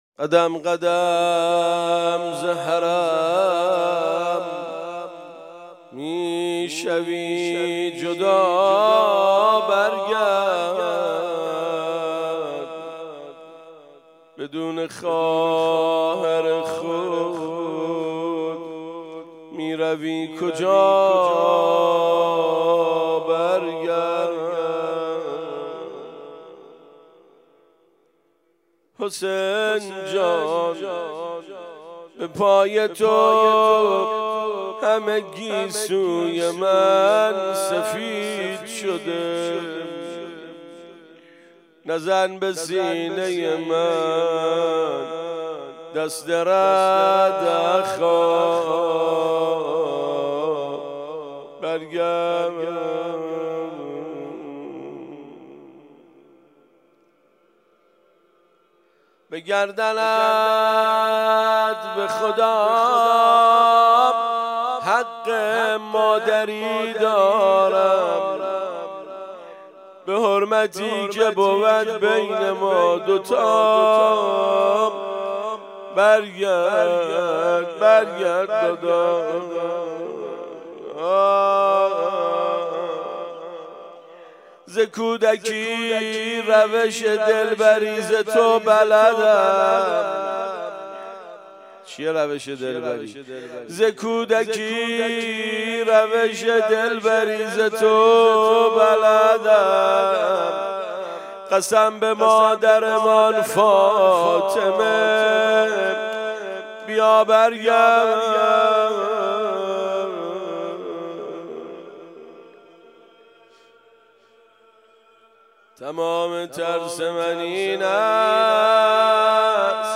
خیمه گاه - روضةالشهداء - روضه وداع